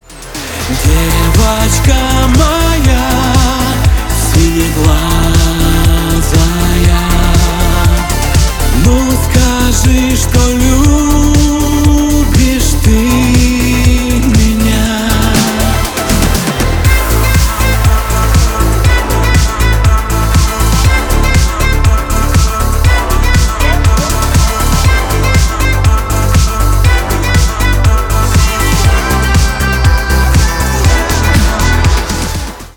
Шансон
кавер